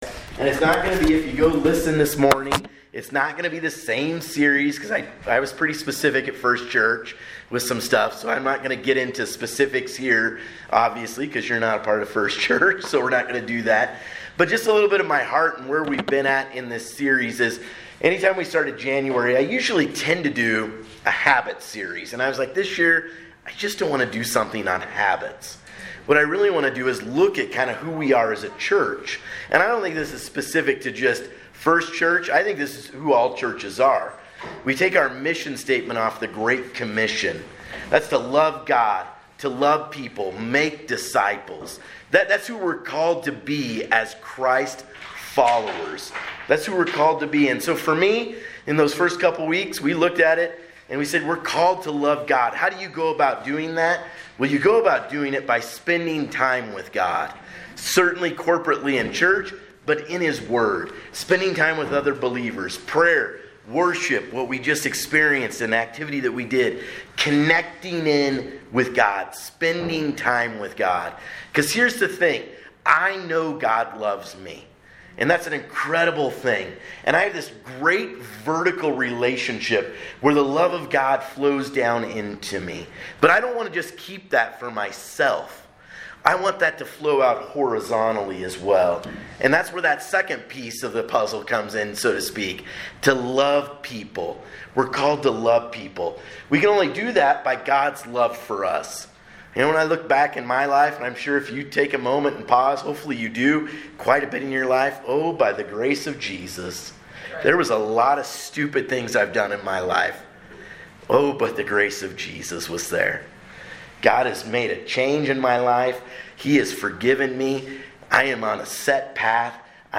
2022 at 1:53 pm and is filed under Sermons .